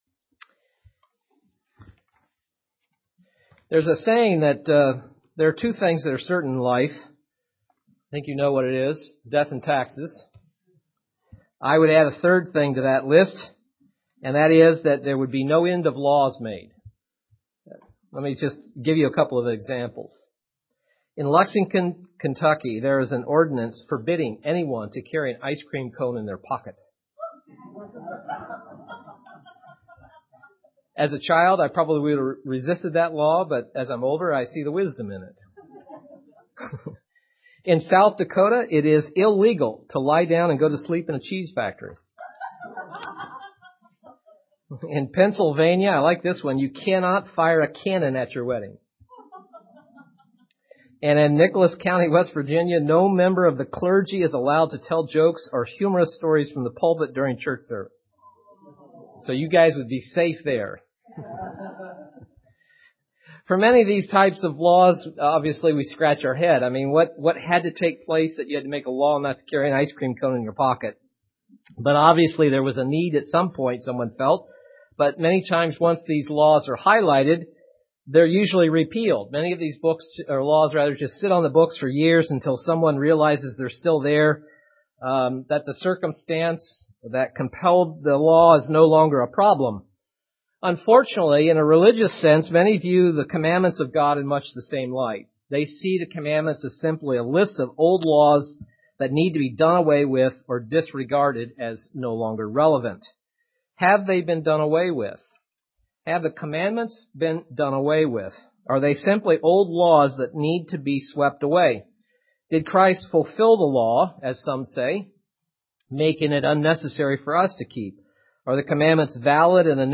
Print Review that the Ten Commandments are still valid UCG Sermon Studying the bible?